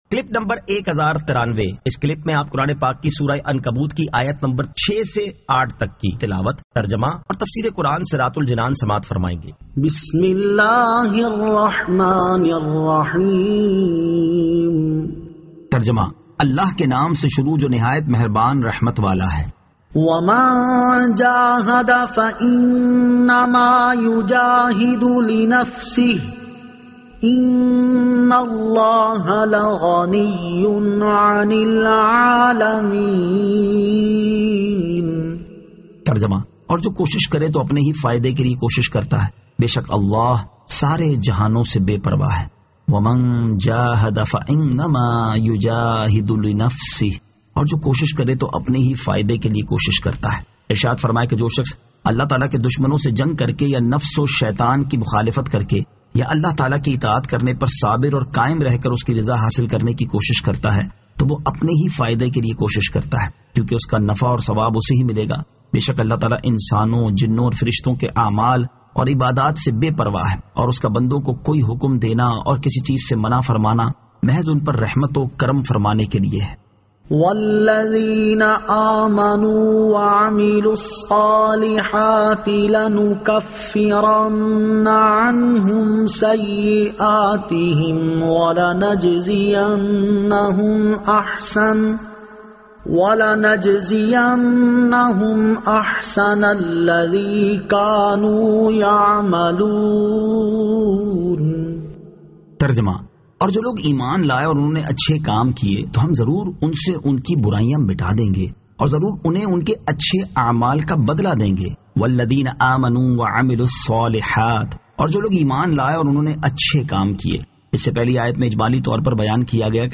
Surah Al-Ankabut 06 To 08 Tilawat , Tarjama , Tafseer